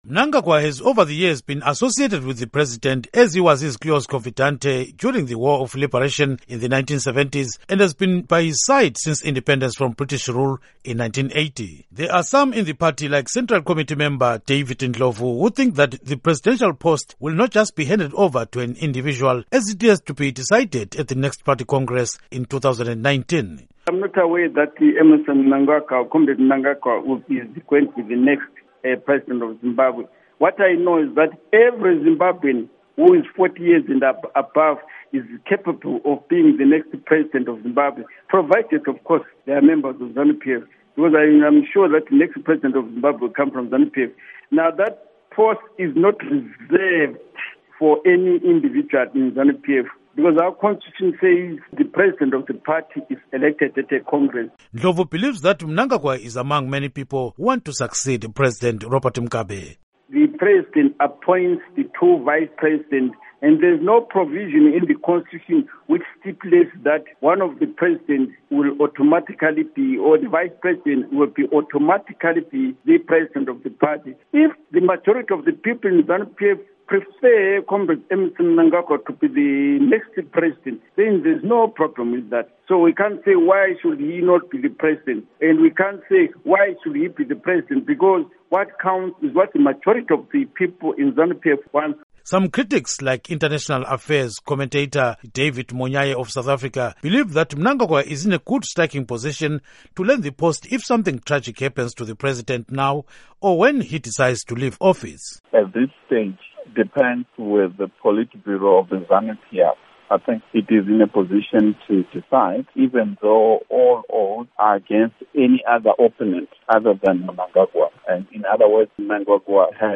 Report on Zanu PF and VP Mnangagwa